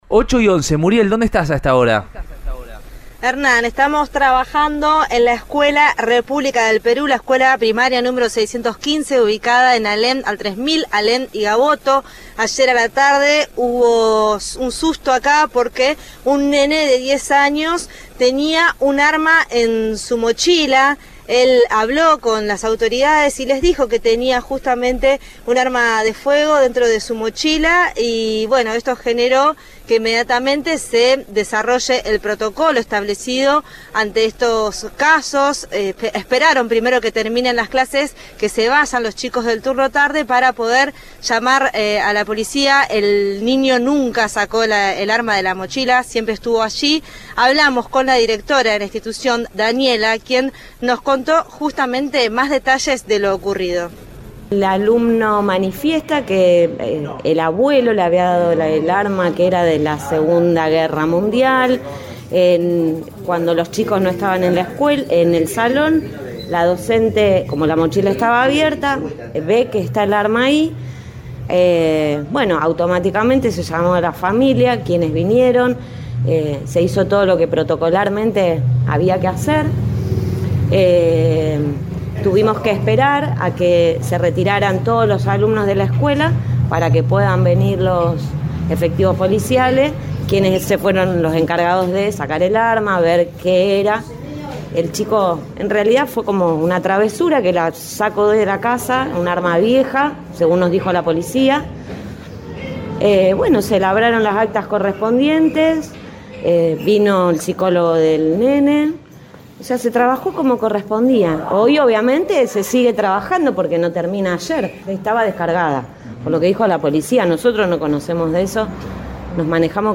habló con el móvil de Cadena 3 Rosario, en Radioinforme 3, y precisó detalles de lo sucedido este lunes.